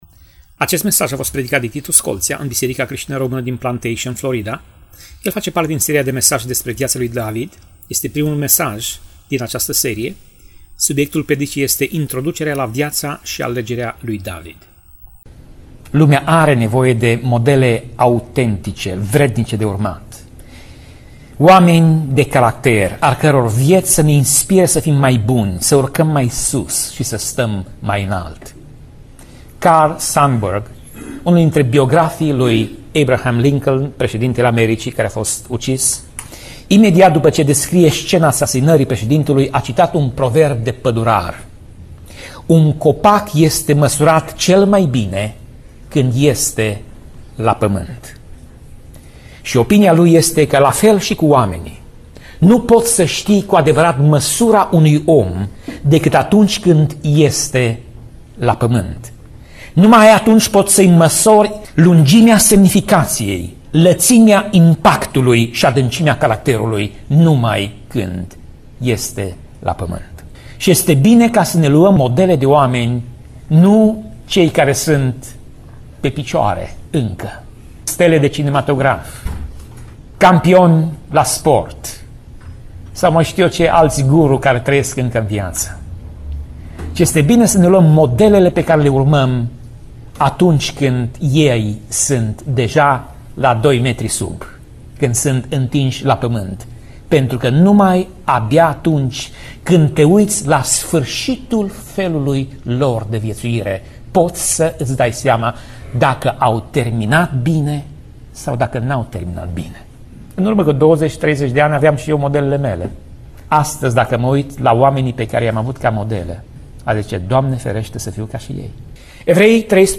Tip Mesaj: Predica Serie: David viata si lucrarea sa